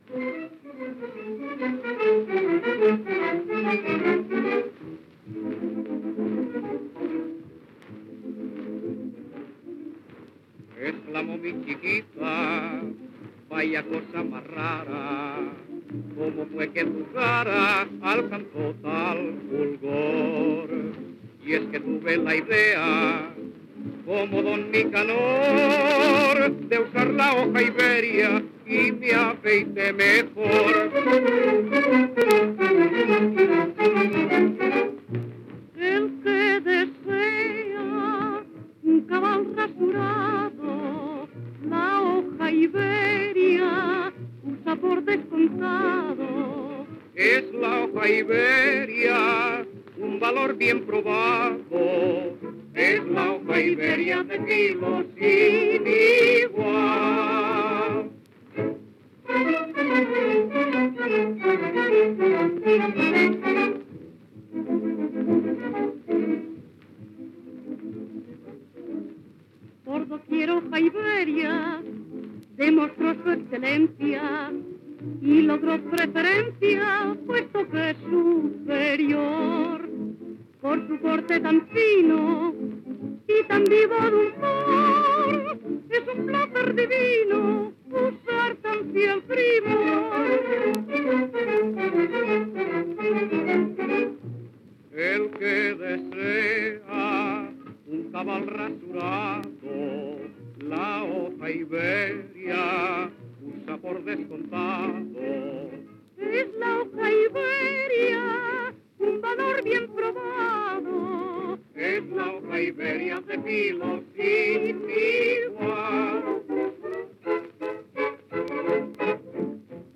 Cançó publicitària